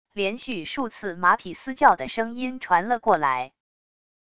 连续数次马匹嘶叫的声音传了过来wav音频